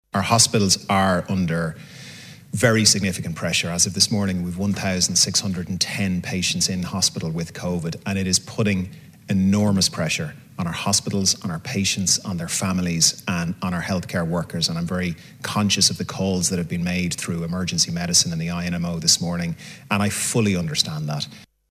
But Health Minister Stephen Donnelly says that’s not the public health advice: